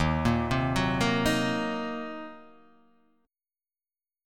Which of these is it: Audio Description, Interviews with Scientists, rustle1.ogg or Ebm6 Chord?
Ebm6 Chord